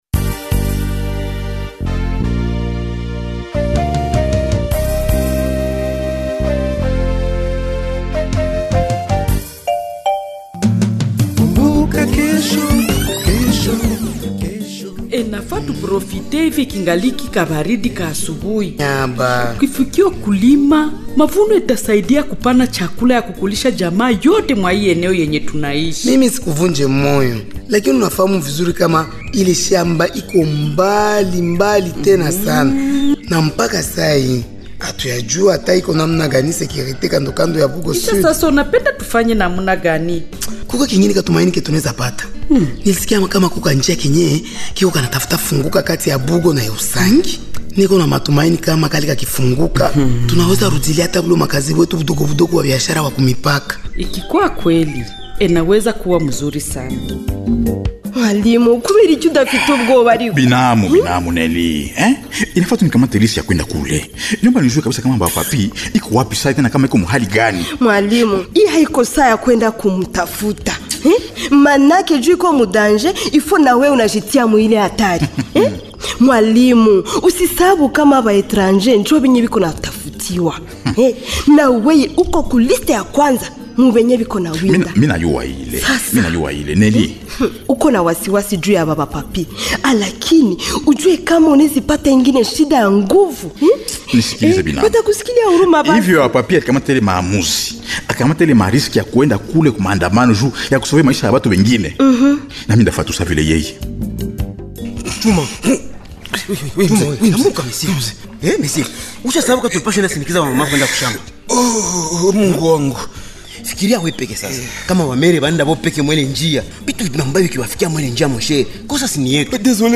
Voici le 983e numéro du feuilleton Kumbuka Kesho du 20 au 26 avril 2026